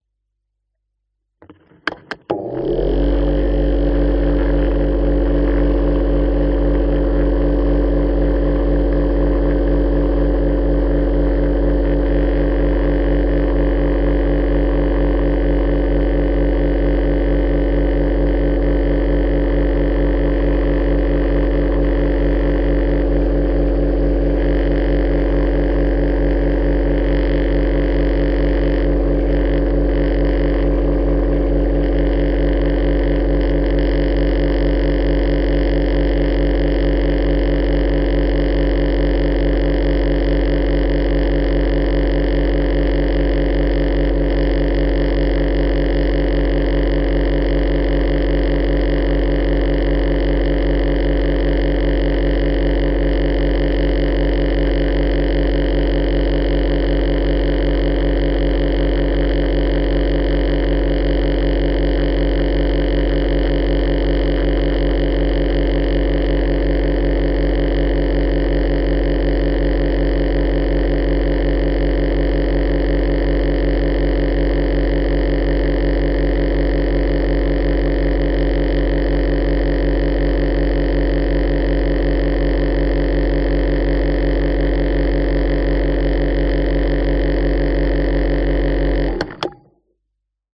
Звуки спа, атмосфера
Гидрофон с вибрационным режимом массажа воды и низкочастотным жужжанием